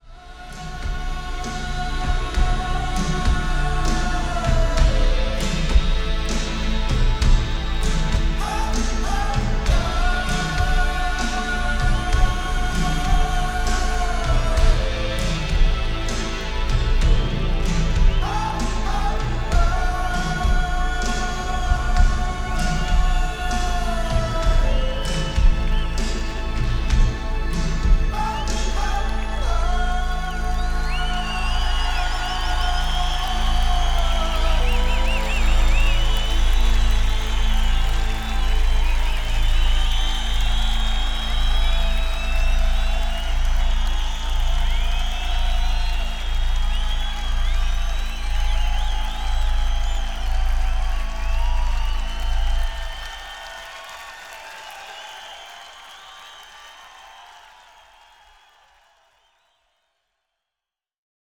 Compare Sample from original CD to newly Digital Refresh.